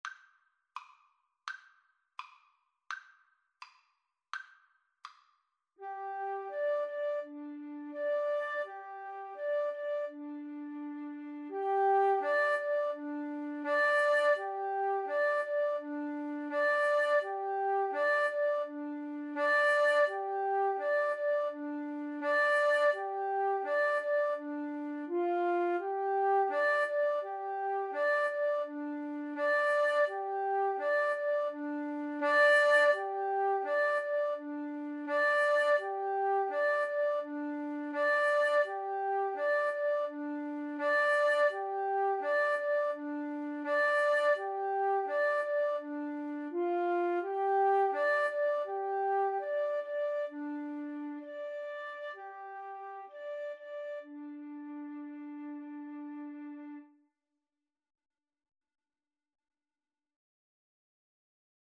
G minor (Sounding Pitch) (View more G minor Music for Flute Duet )
Steady two in a bar ( = c. 84)